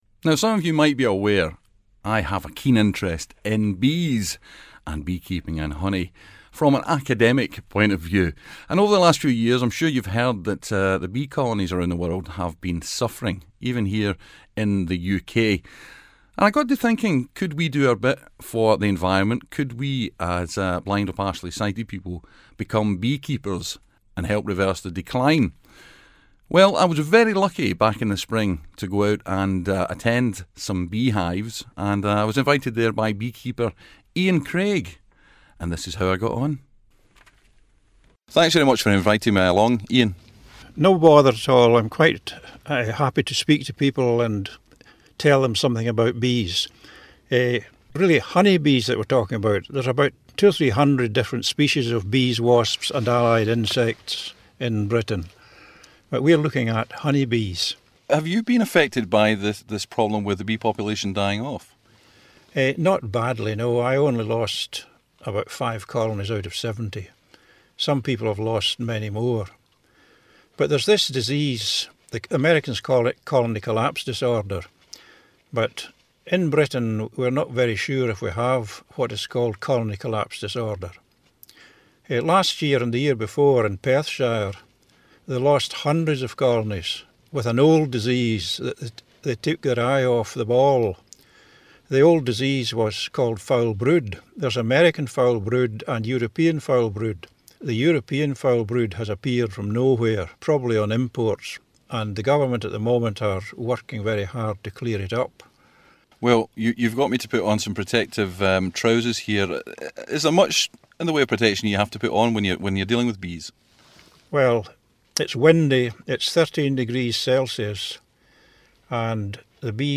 we’re sharing a very special interview.